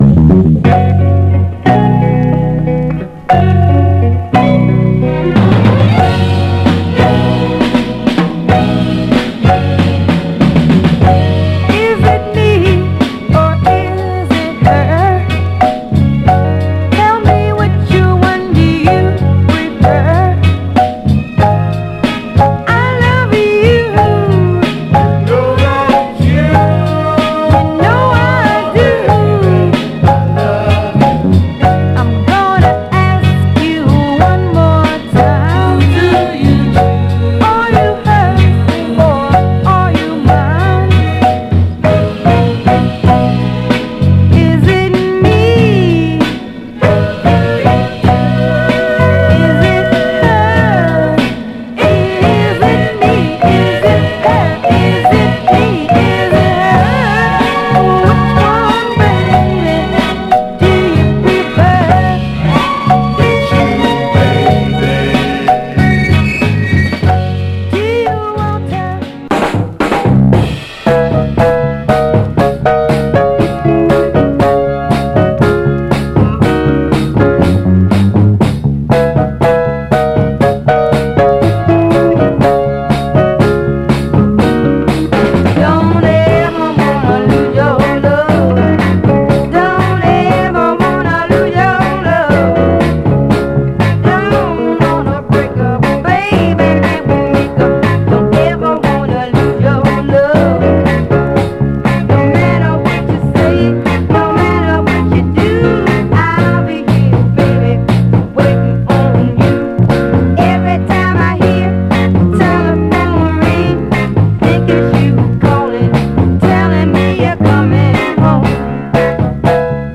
キュートなメロウ・ヴォーカルが炸裂するクロスオーヴァーなミッド
※試聴音源は実際にお送りする商品から録音したものです※